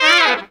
HARM RIFF 14.wav